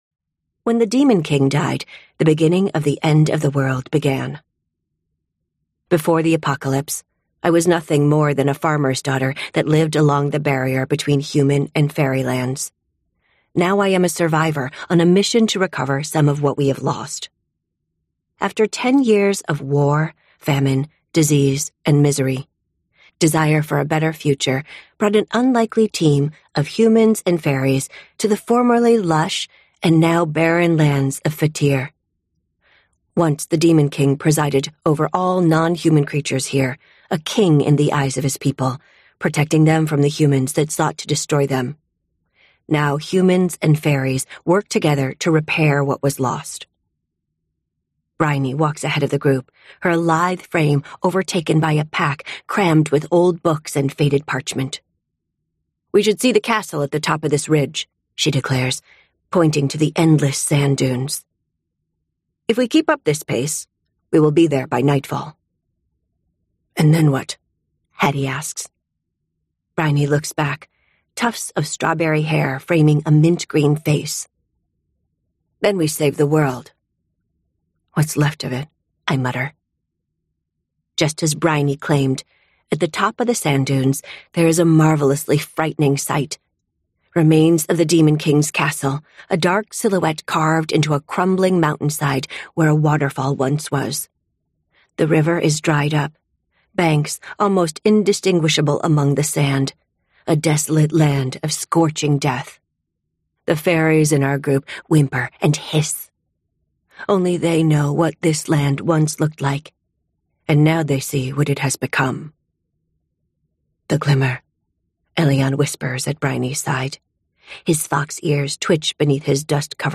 Book 1 Unabridged Audiobook Categories